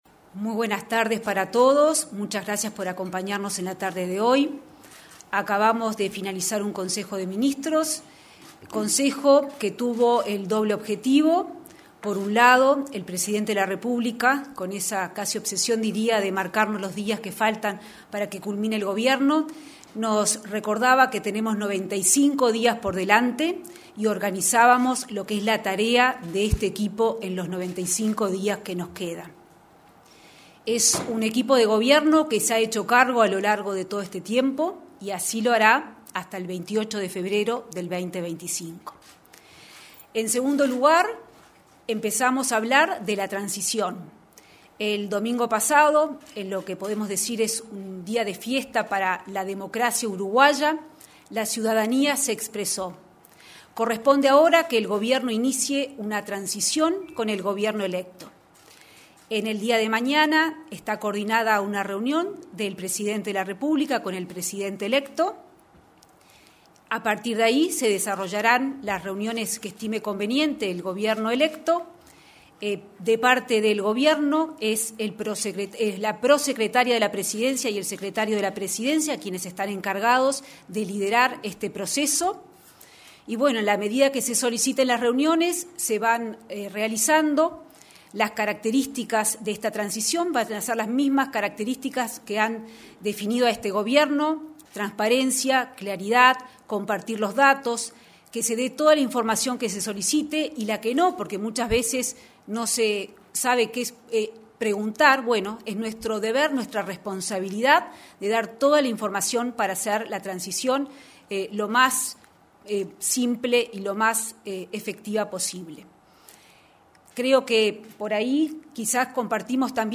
Conferencia de prensa tras Consejos de Ministros
Conferencia de prensa tras Consejos de Ministros 26/11/2024 Compartir Facebook X Copiar enlace WhatsApp LinkedIn Este martes 26, se realizó una conferencia de prensa luego del Consejo de Ministros en la Torre Ejecutiva. En la oportunidad, se expresaron la ministra de Economía y Finanzas, Azucena Arbeleche; la ministra de Salud Pública, Karina Rando, y el ministro de Relaciones Exteriores, Omar Paganini.